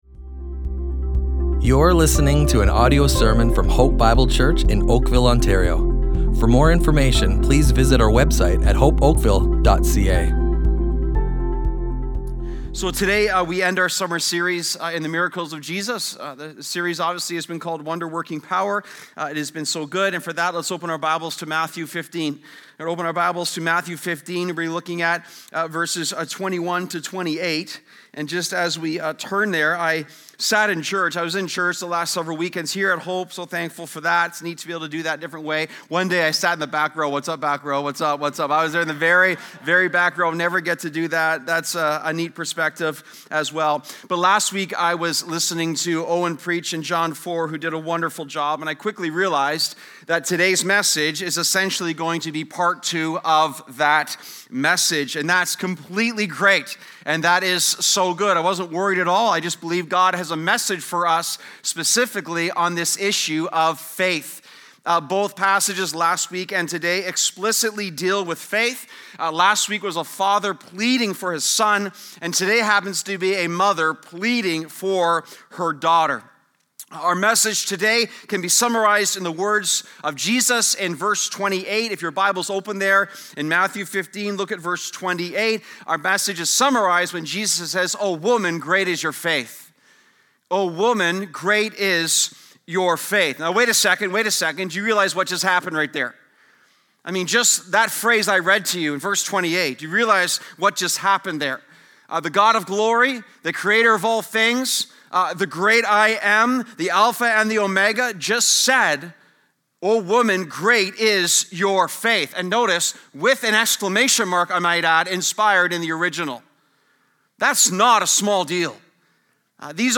Hope Bible Church Oakville Audio Sermons Podcast - Wonder Working Power // Wonder Working Faith | Free Listening on Podbean App